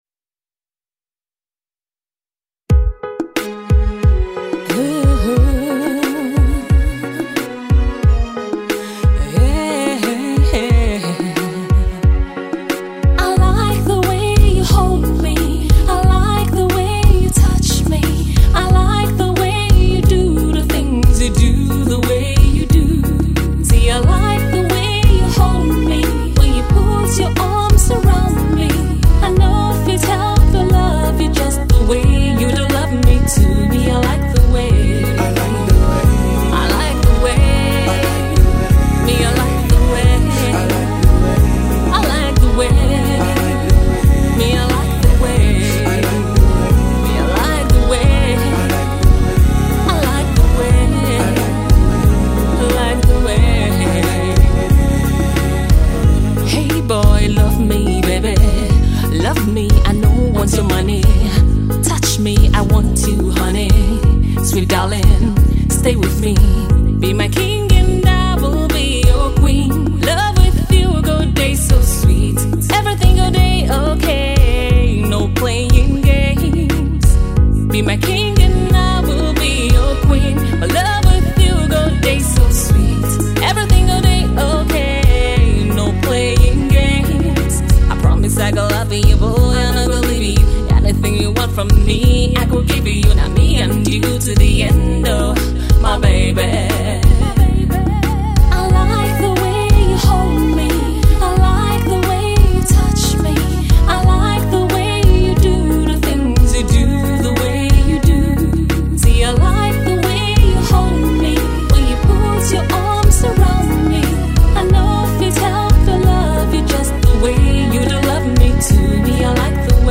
with an Afro Pop jingle